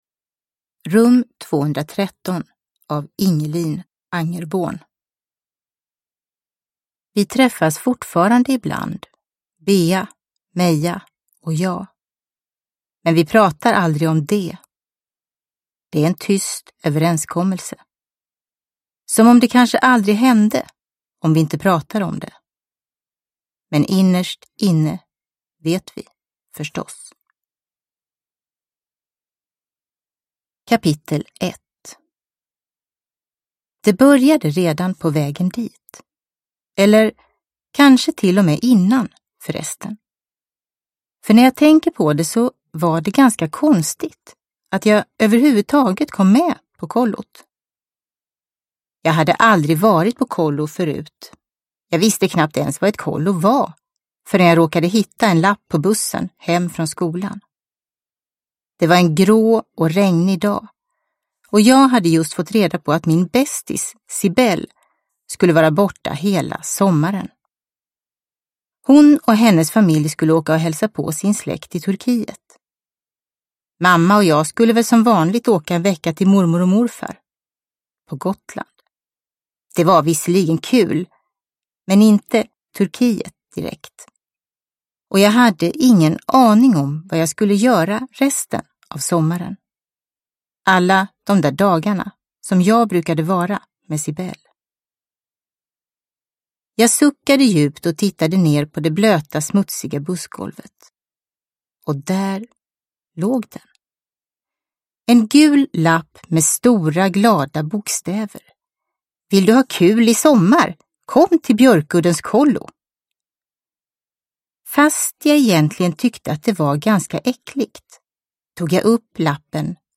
Rum 213 – Ljudbok – Laddas ner